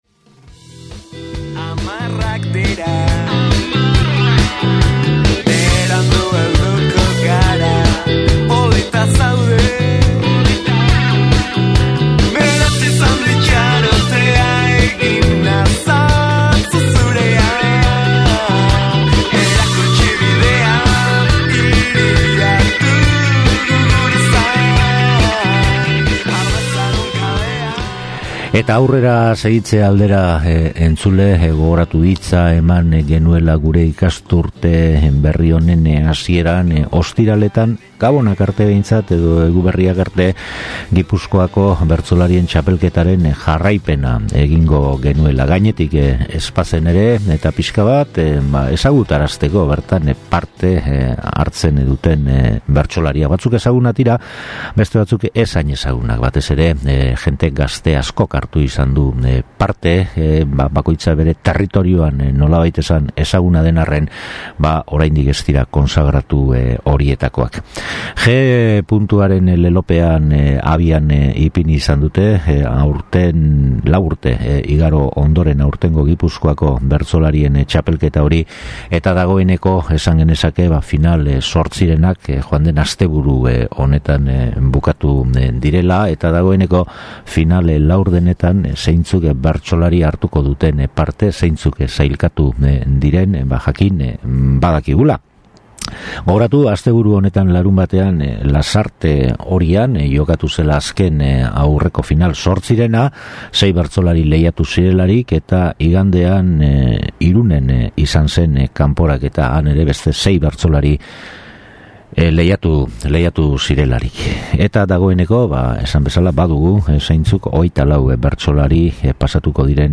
SOLASALDIA
“Gpuntua”, final zortzirenak bukatuta, laurdenetan hasteko prest dago: joan den larunbatean Lasarten egin saioaren zenbait pasarte entzuteko aukera izango duzu entzule.